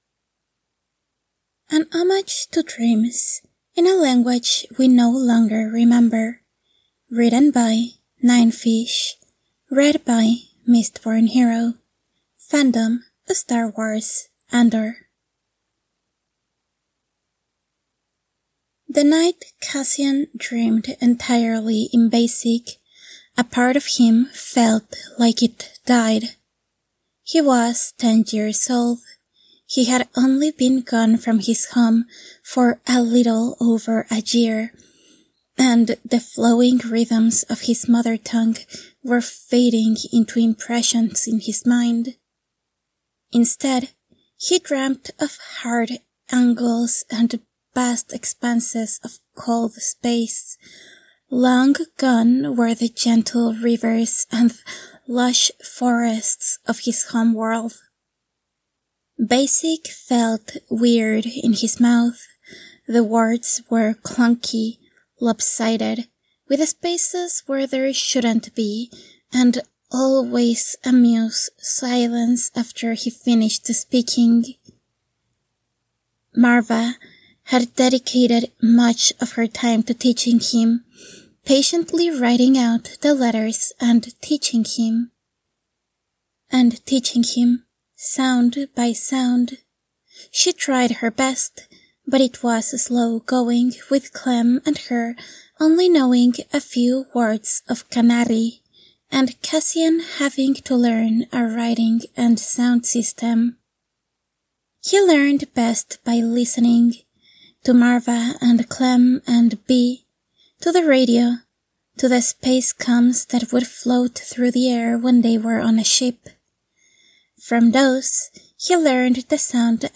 with SFX: download mp3: here (r-click or press, and 'save link') [11 MB, 00:11:06]